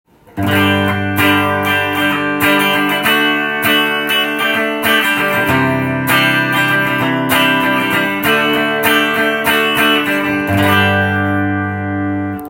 このギターを試しに弾いてみました
当たり前ではありますが、１９７０年代の音がします。アナログな昔ながらの懐かしいギターサウンドです。